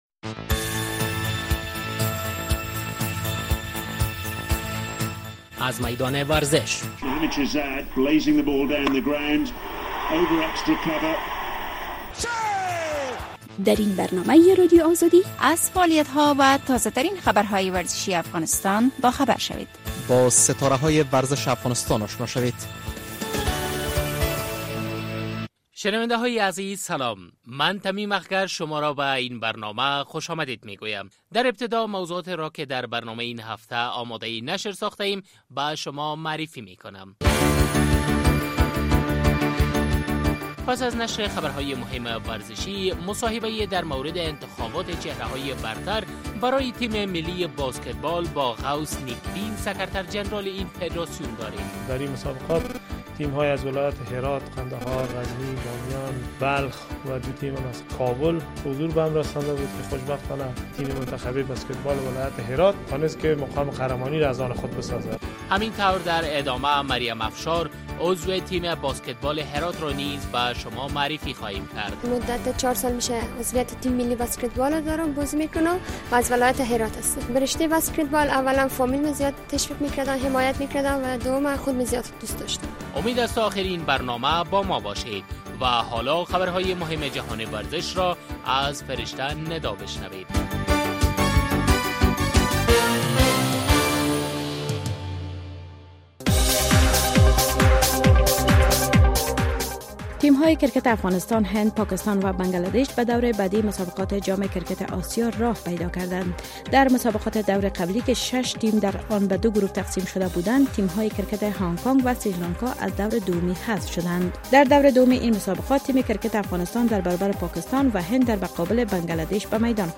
برنامۀ ورزش